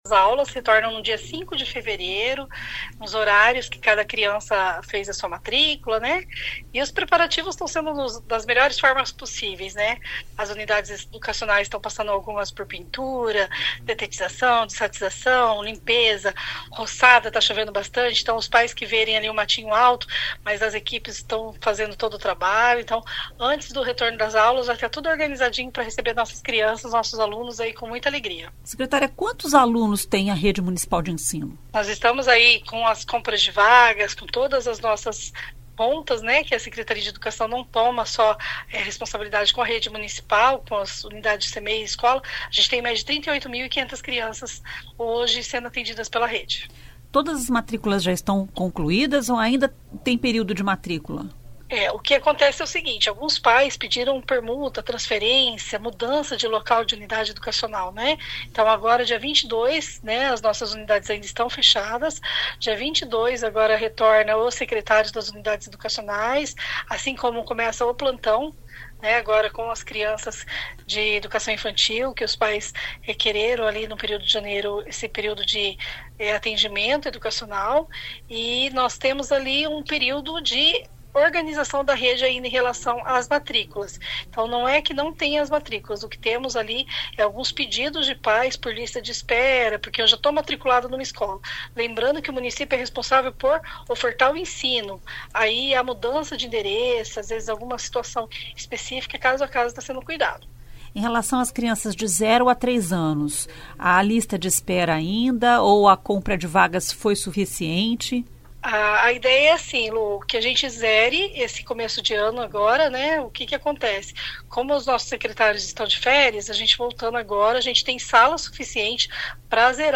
Ouça o que diz a secretária: